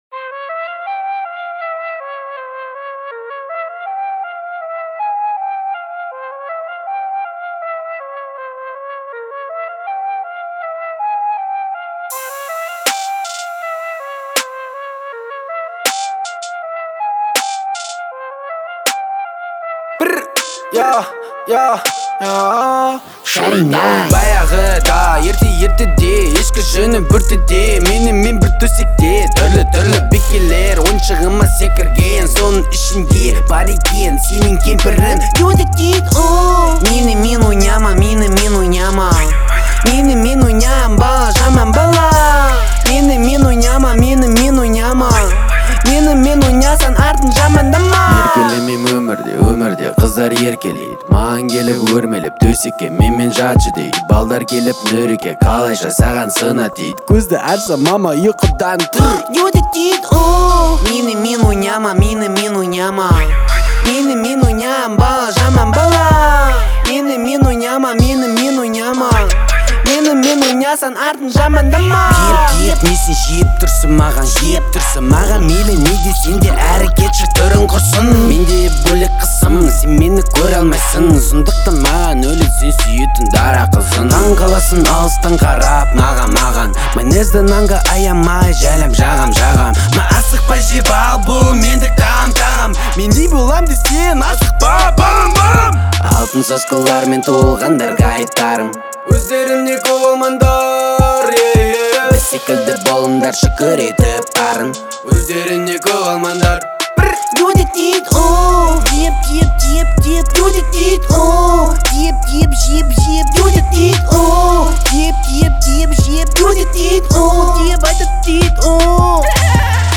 это яркий пример современного рок-звучания